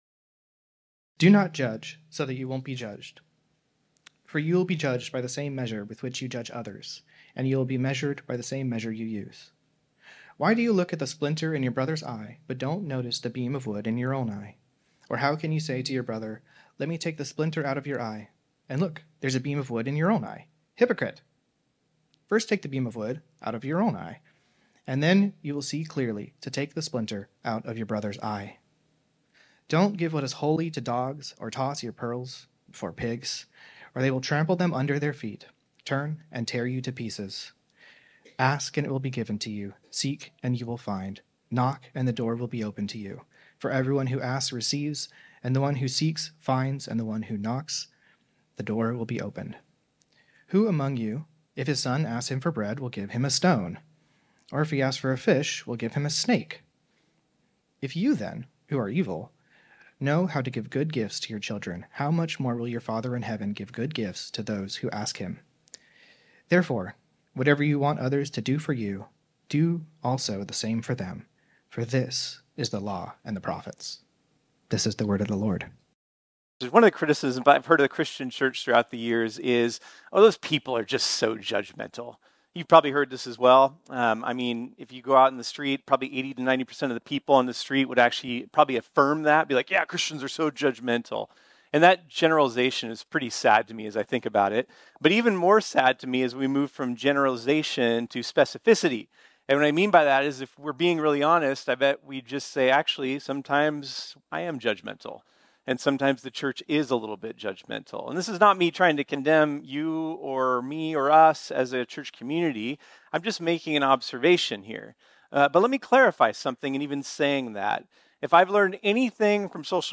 This sermon was originally preached on Sunday, March 10, 2024.